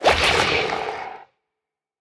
Minecraft Version Minecraft Version latest Latest Release | Latest Snapshot latest / assets / minecraft / sounds / mob / drowned / convert2.ogg Compare With Compare With Latest Release | Latest Snapshot